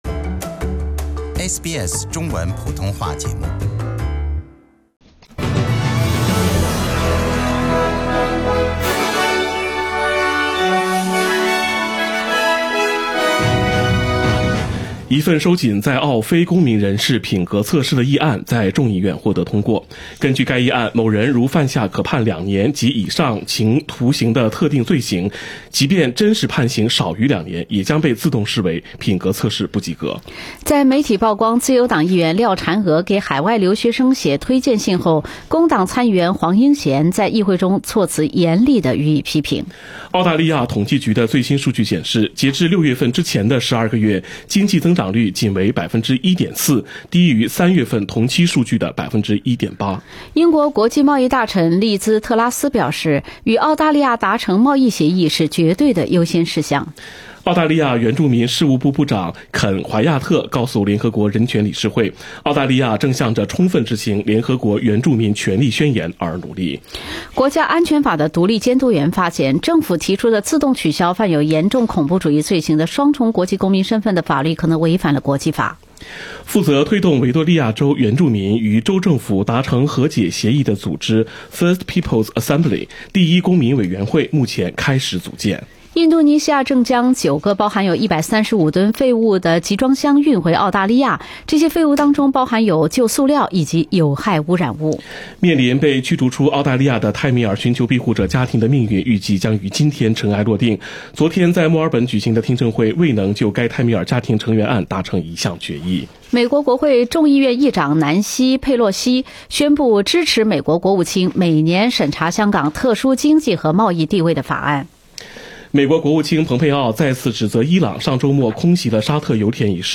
SBS早新闻（9月19日）